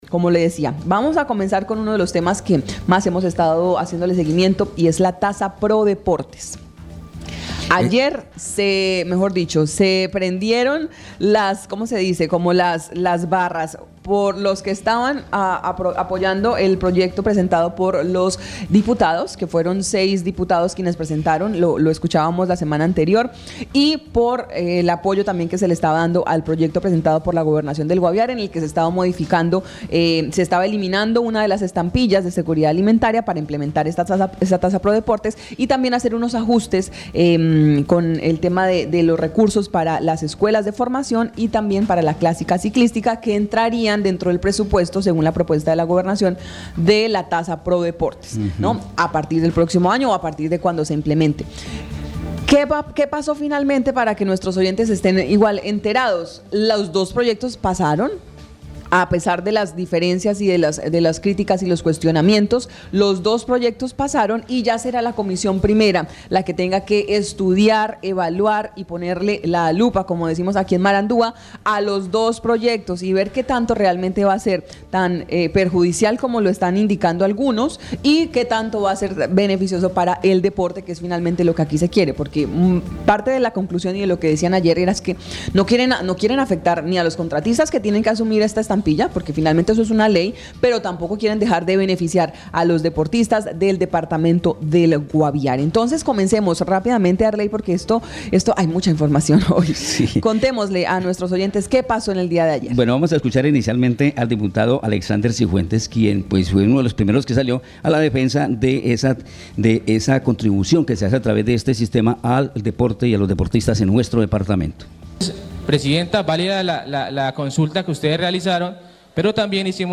La aprobación se realiza después una larga y calurosa discusión entre los diputados sobre los beneficios y perjuicios que traería al departamento y a contratistas el recaudo, que se hará a través de este instrumento con el que se busca recaudar 2.500 millones anuales para apoyar y financiar las actividades del deporte en el Guaviare.